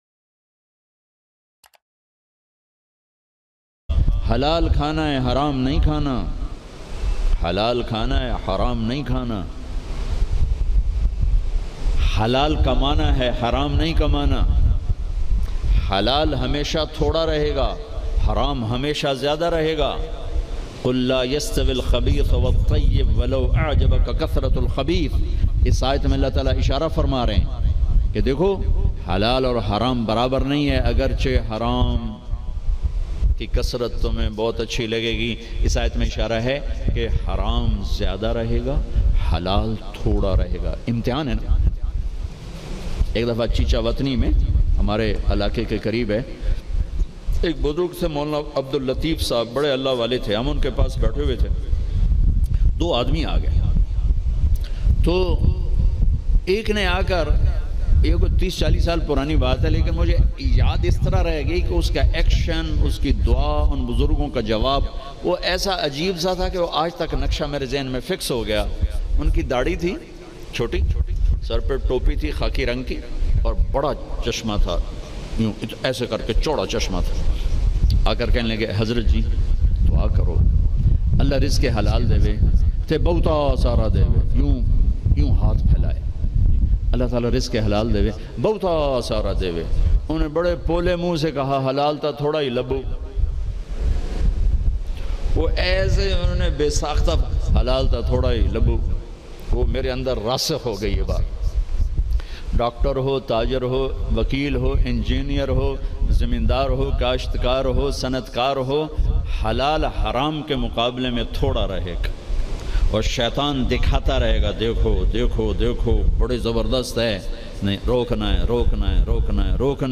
Maulana Tariq Jameel Latest Bayan Haram Khane Walo Ko Dolat Allah Kyun Deta Hai.mp3